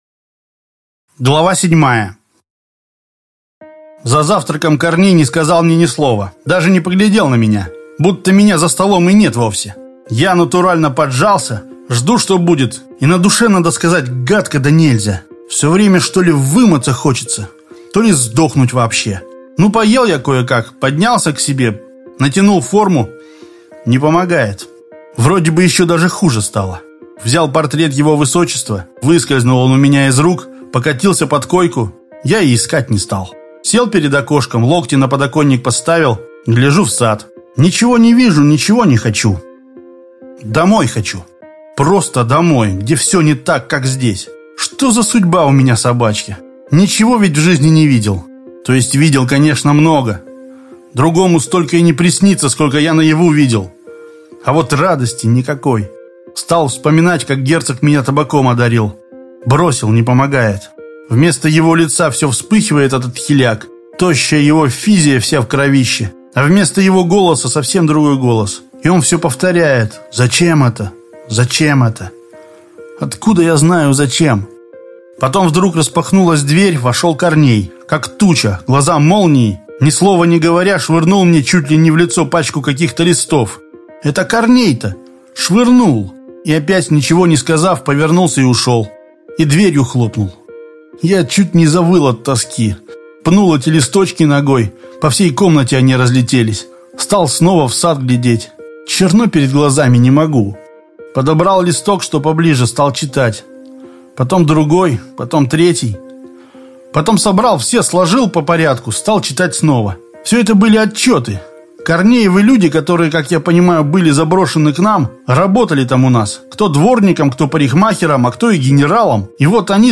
Аудиокнига Парень из преисподней. Часть 7.